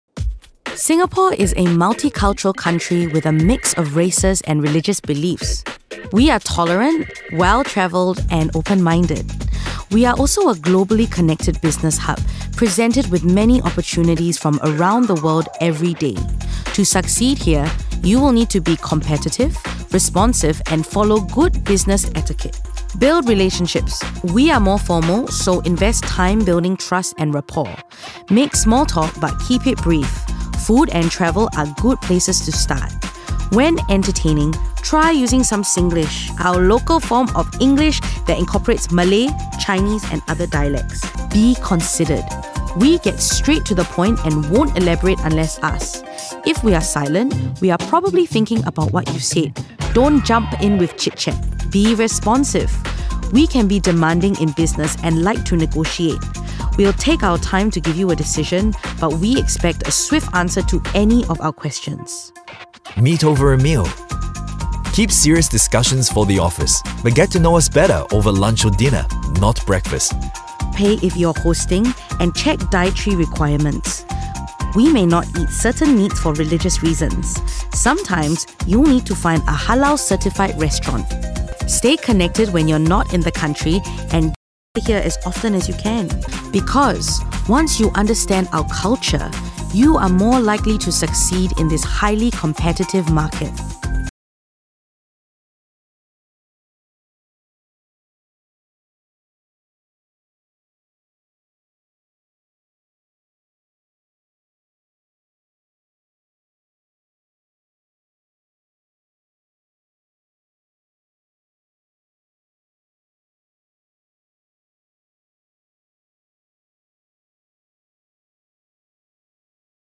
singapore | natural
husky
Singapore_English_Voice_Reel.mp3